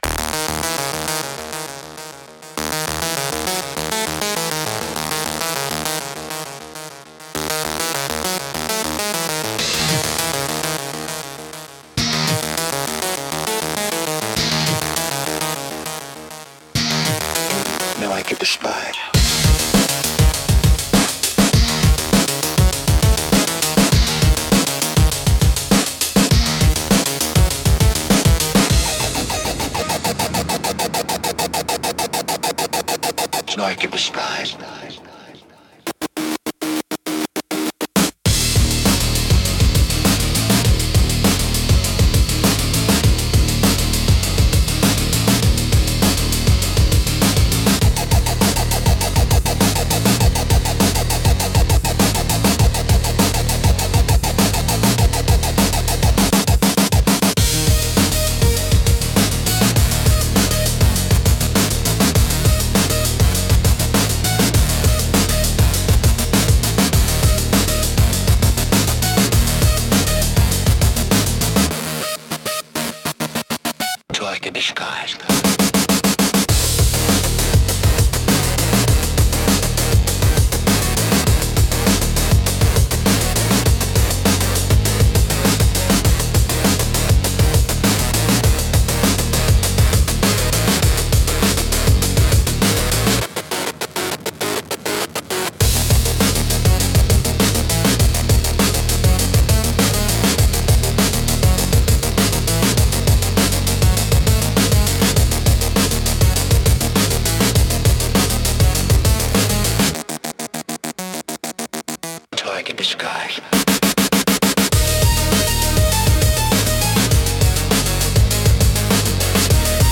躍動感を生み出し、観客やプレイヤーのテンションを上げる効果が高く、飽きさせないペースで強いインパクトを与えます。